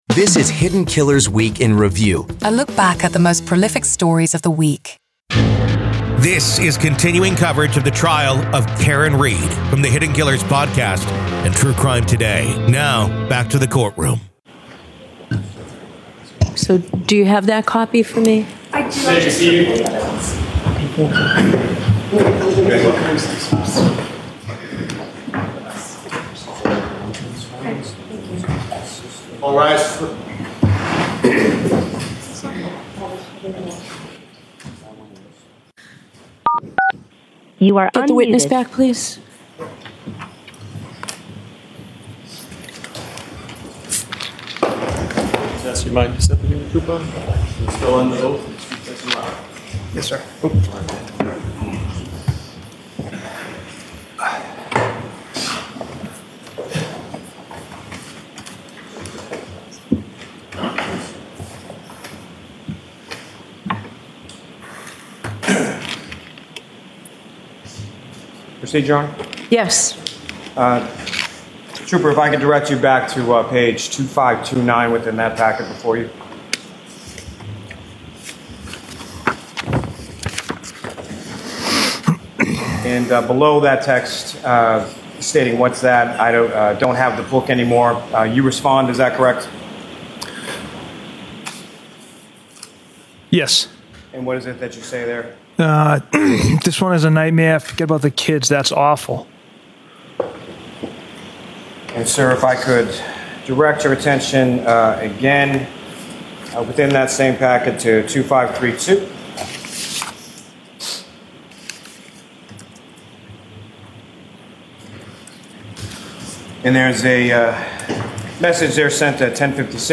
SOME LANGUAGE MAY BE OFFENSIVE: